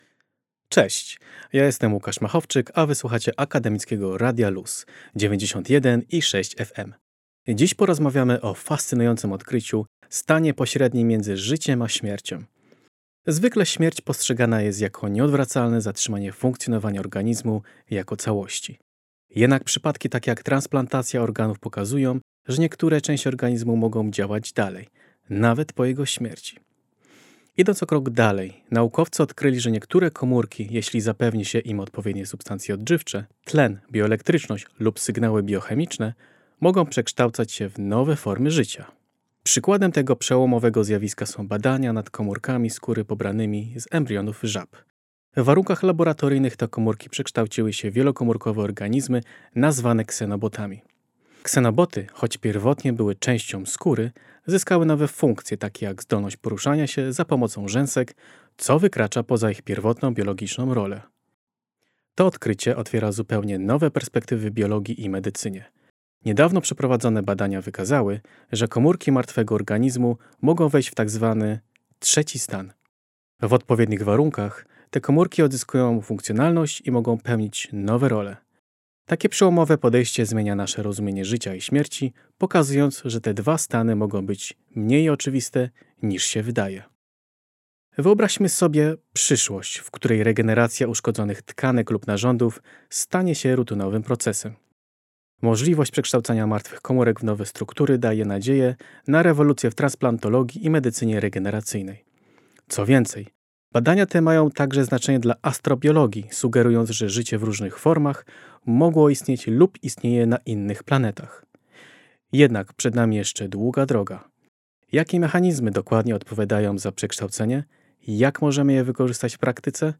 Zycie-i-Smierc-Felieton.mp3